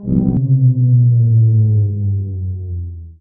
power_off1.wav